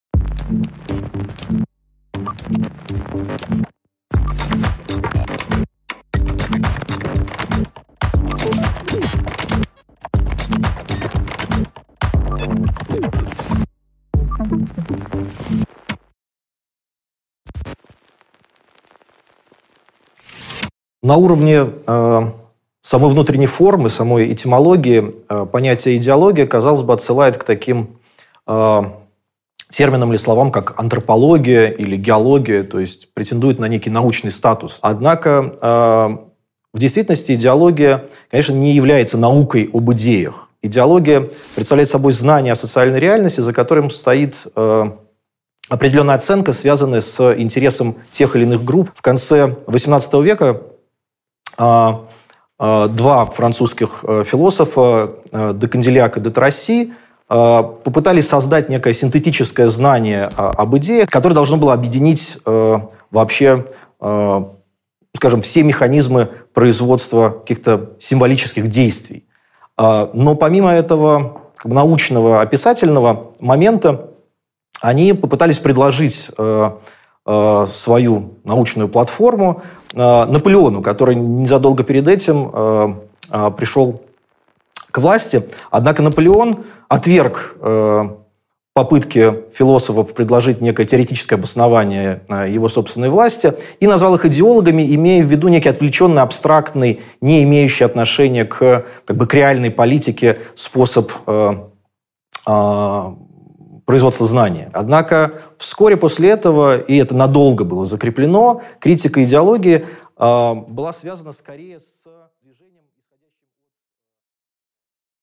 Аудиокнига От идеи к товару | Библиотека аудиокниг
Прослушать и бесплатно скачать фрагмент аудиокниги